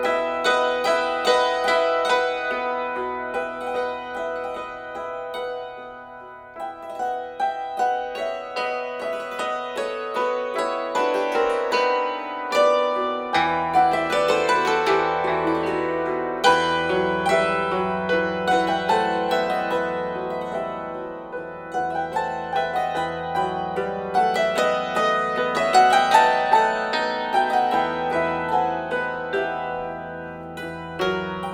Hackbrettquartett